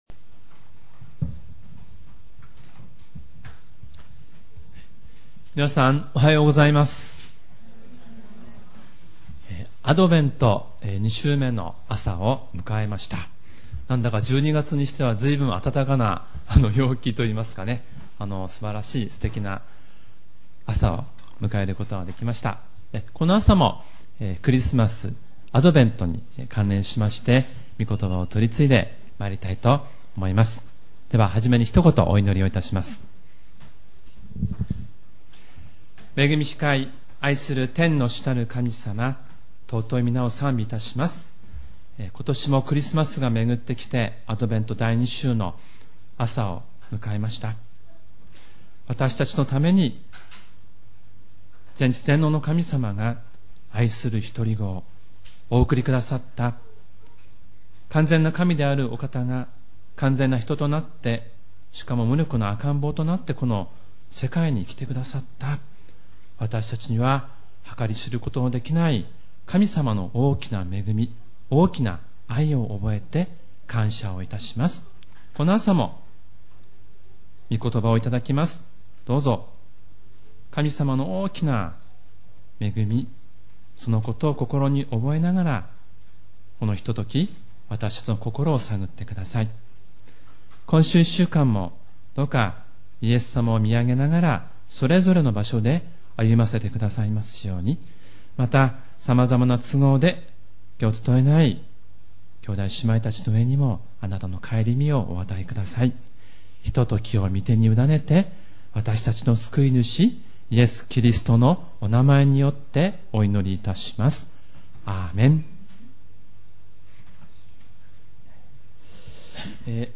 主日礼拝メッセージ